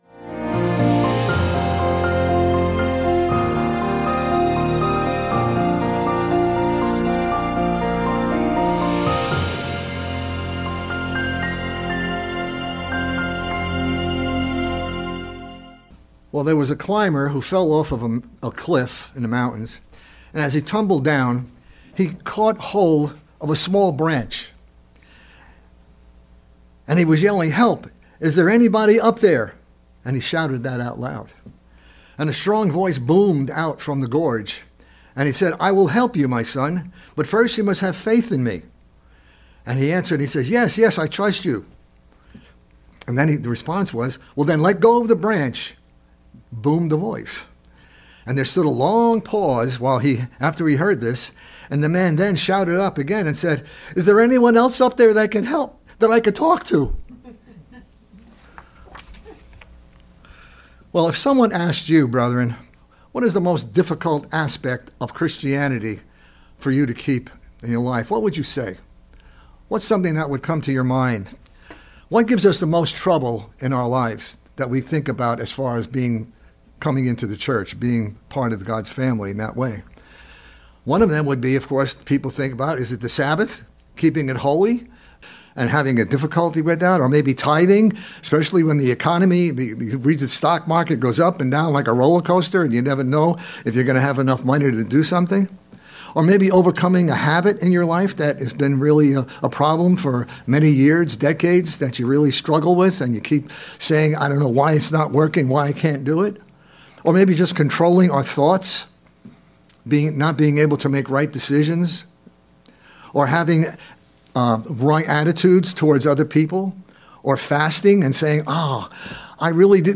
Sermons
Given in Charlotte, NC Hickory, NC Columbia, SC